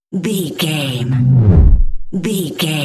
Deep whoosh pass by
Sound Effects
dark
futuristic
intense